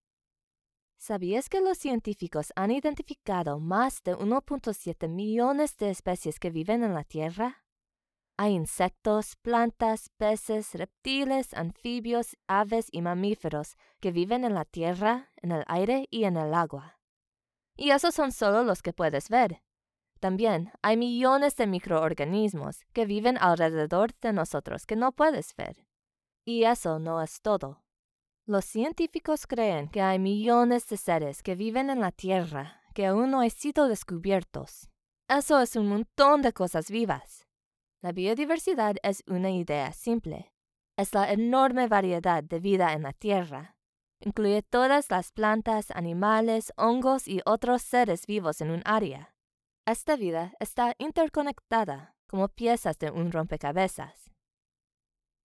Demo
Child, Teenager, Young Adult, Adult
Has Own Studio
spanish | latin american